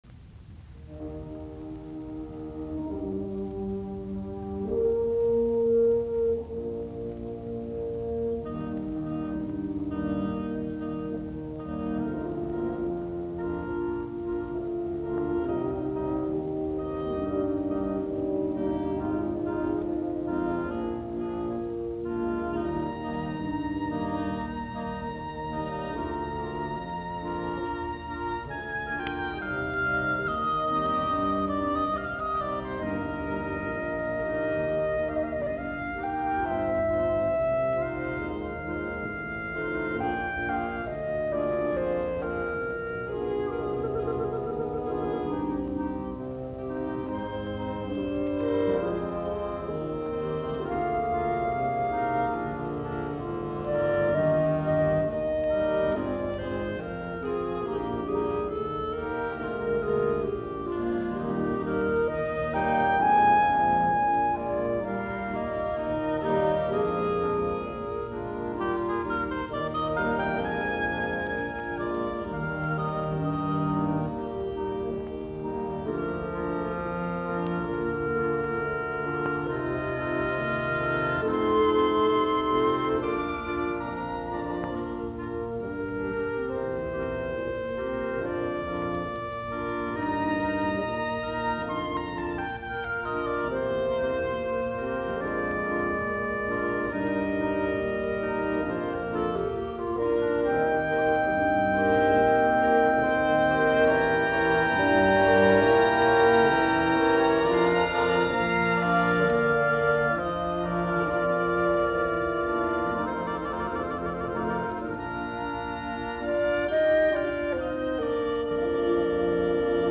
puhallinork.
B-duuri